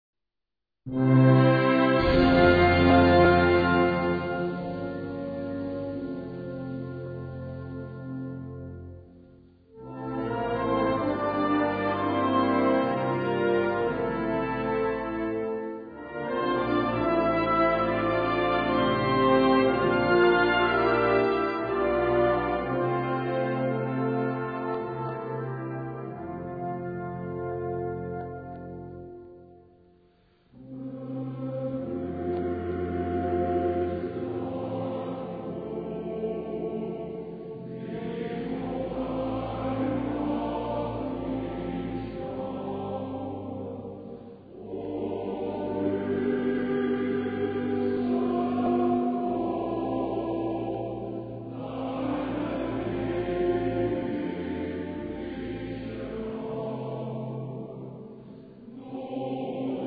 Gattung: für Männerchor und Blasorchester
Besetzung: Blasorchester